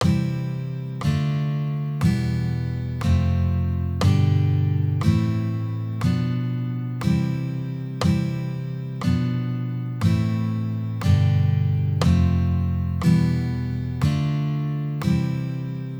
Let's listen to the above major and minor chords in the key of C, played in order and then reverse order.
Chords in the key of C
chromatic-chords.wav